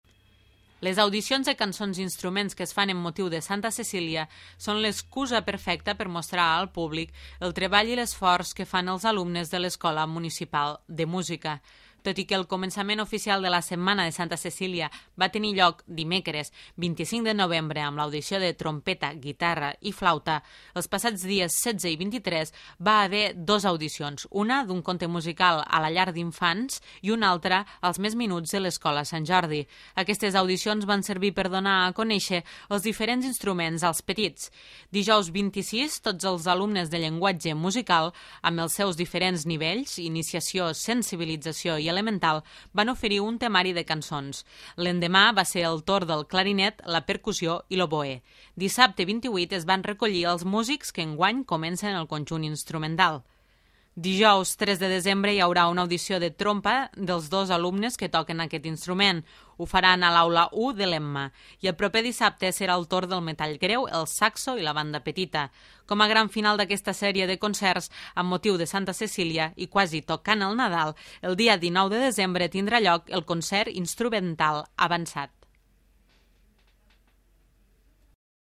Les audicions de cançons i instruments que es fan en motiu de Santa Cecília són l'excusa perfecta per mostrar al públic el treball i l'esforç que fan els alumnes de l'Escola Municipal de Música.